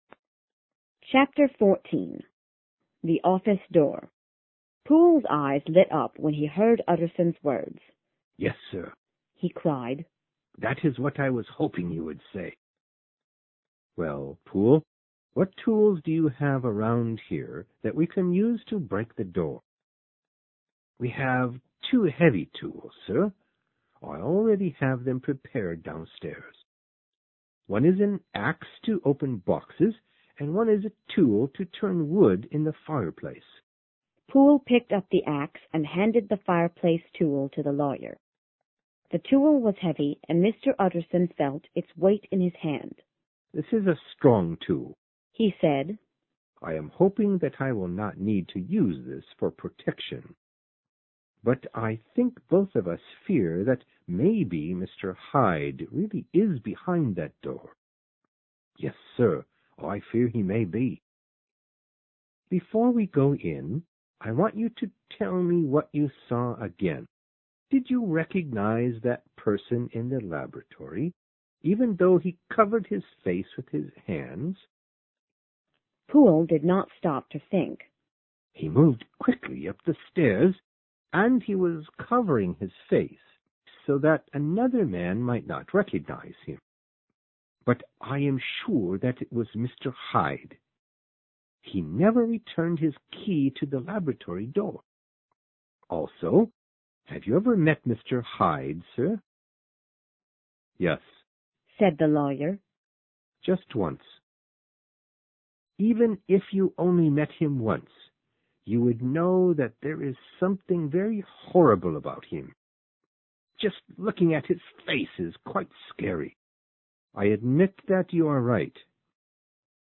有声名著之化身博士14 听力文件下载—在线英语听力室